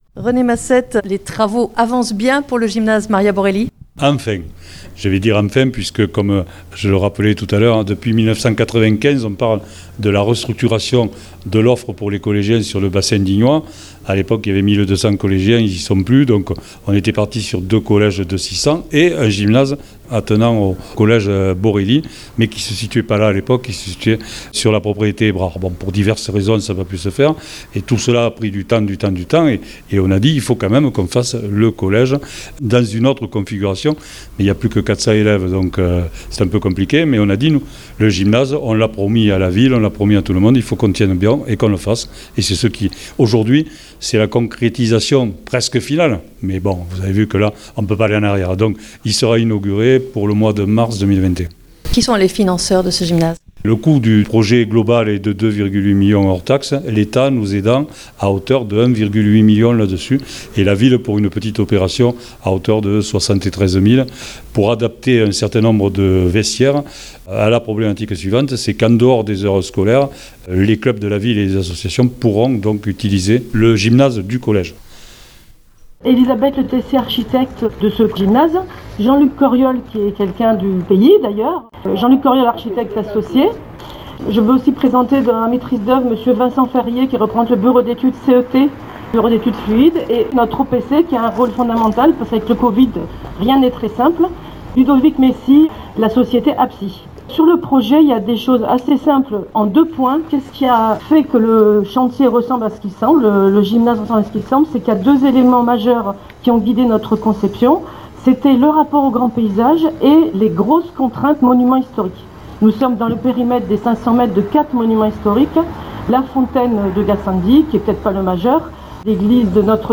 Hier à Digne les bains, visite du chantier de construction du gymnase Maria Borrély. Commencés en mars 2020, et avec les aléas de la Covid 19, les travaux se poursuivent avec l’élévation des murs et la réalisation de la salle principale.